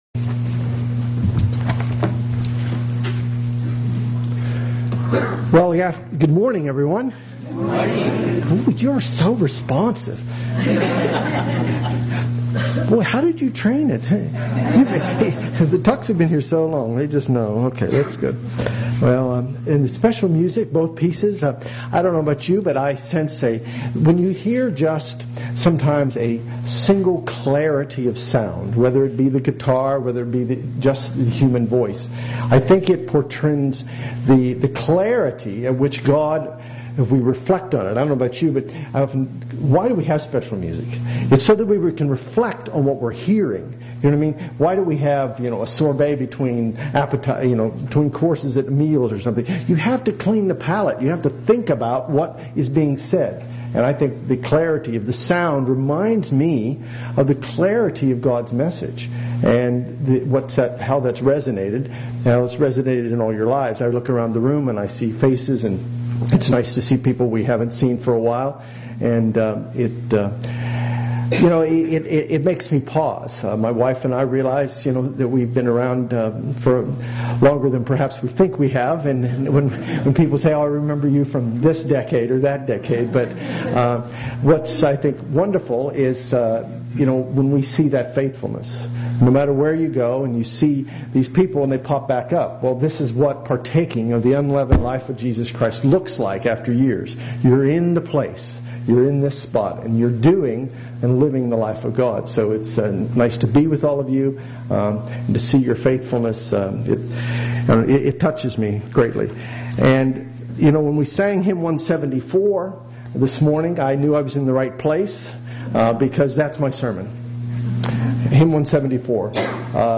UCG Sermon Notes A partial set of notes and Scriptures: ROUTE OF DELIVERANCE Ex. 15 the route of deliverance Joh 17:18 As thou hast sent me into the world, even so have I also sent them into the world.